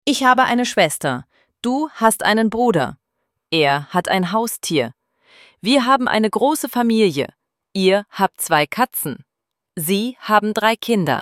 izgovor -Glagol haben – Präsens:
ElevenLabs_Text_to_Speech_audio-42.mp3